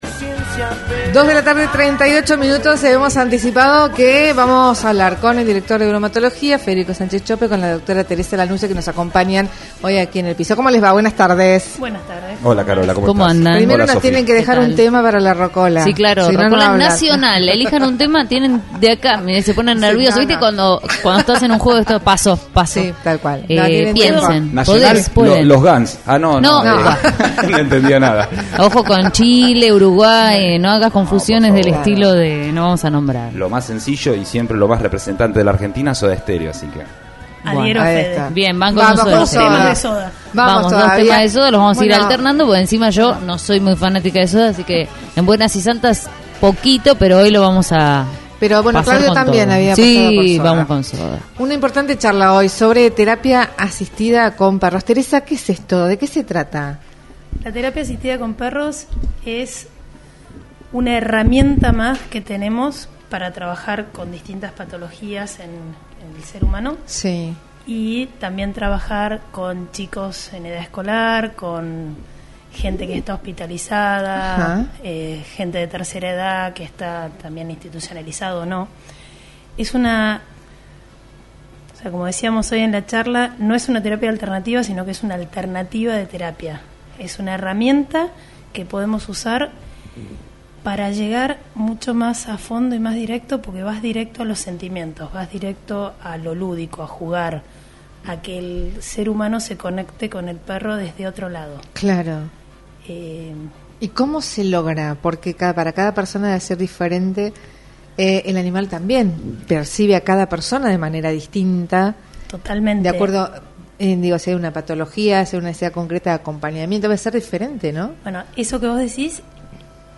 Tandil FM Entrevista